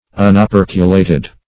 Unoperculated \Un`o*per"cu*la`ted\, a. Destitute of an operculum, or cover.